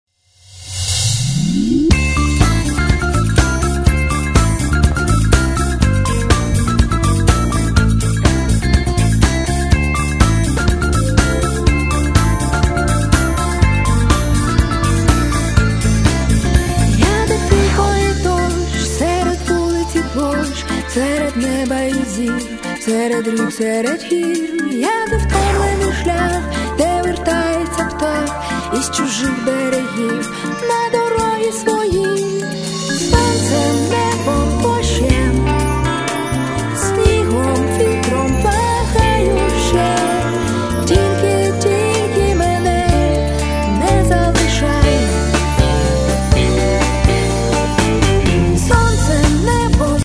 Home » CDs» Rock My account  |  Shopping Cart  |  Checkout